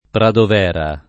Pradovera [ pradov $ ra ]